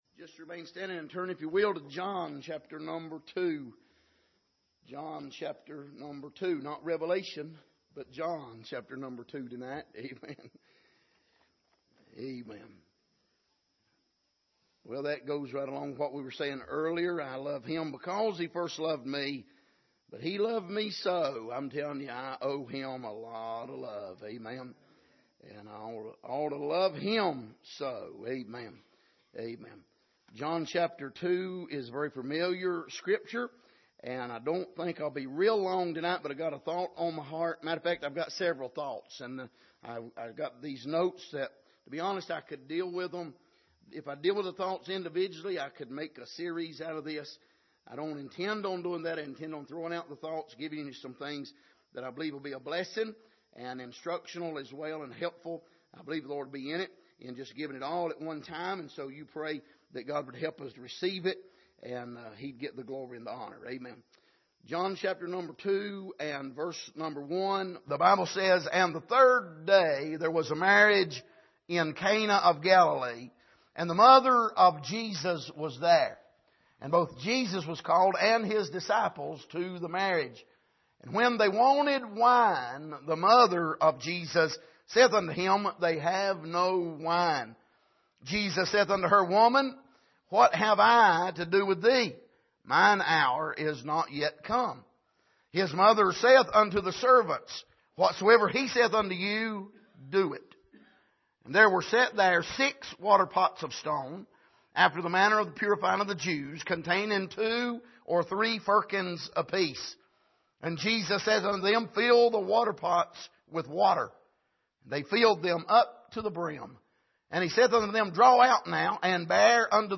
Passage: John 2:1-11 Service: Sunday Evening